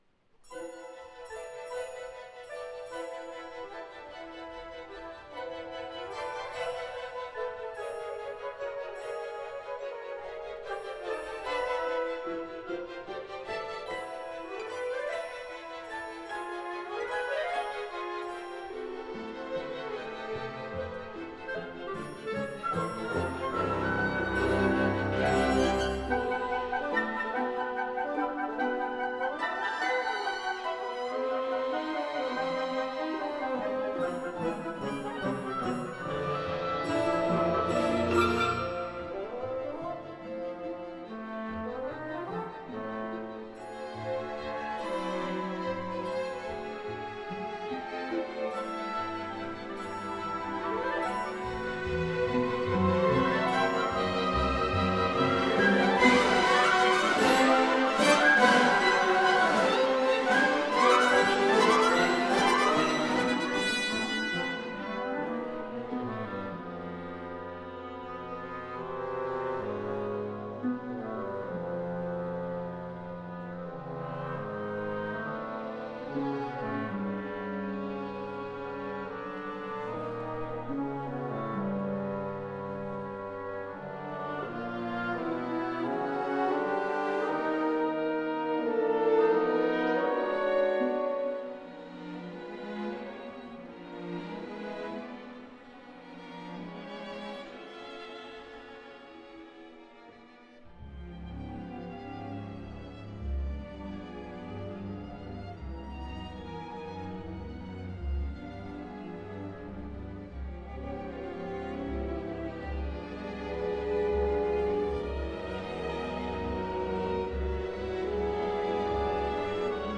Period: 20th Century
Genre: Impressionist
• The melody in the flute at the beginning and how it is played by different instruments throughout the piece.
• Busy musical lines for every instrument, creating an interesting musical texture.
• The different dynamics that add colour to the music. Some parts are strong, bright and alert whilst others are softer, more dreamlike and mysterious.
• Towards the end the music builds in dynamics to a sparking finish.
Listen to the BBC Concert Orchestra performance of D'un matin de printemps (mp3)